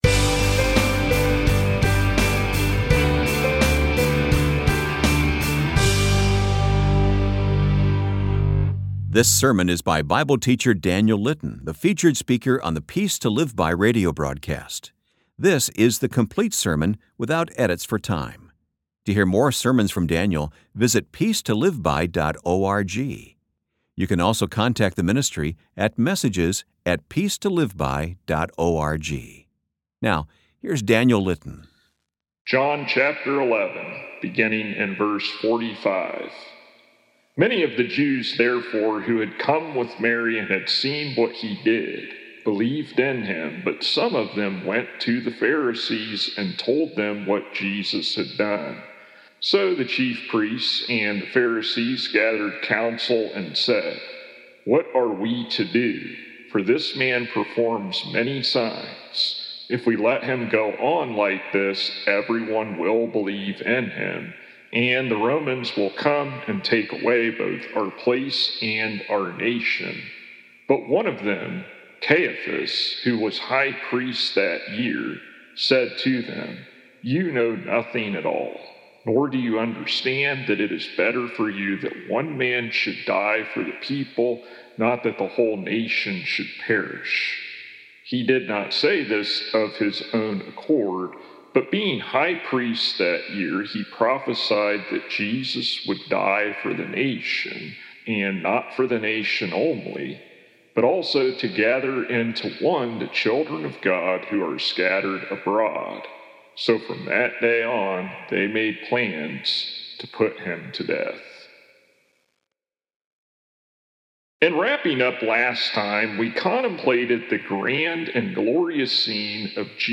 According to John Full Sermons